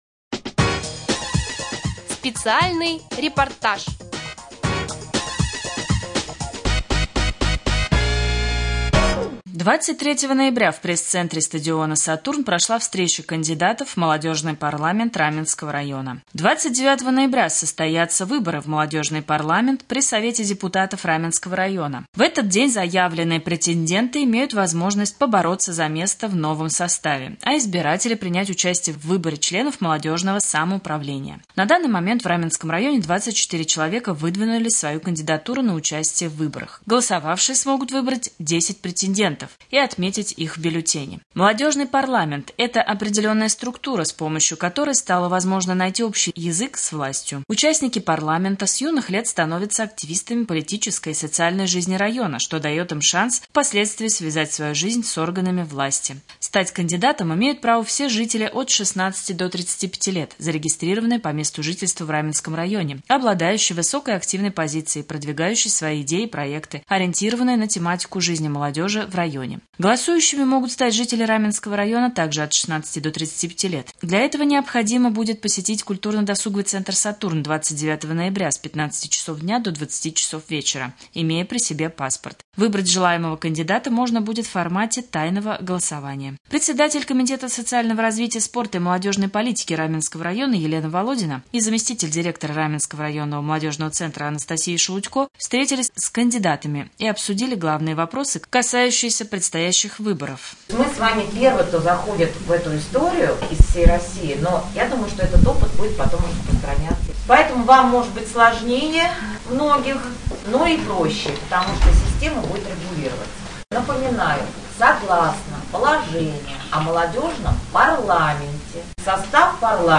В КДЦ «Сатурн» состоялось торжественное мероприятие посвященное 100-летнему юбилею профсоюзной организации.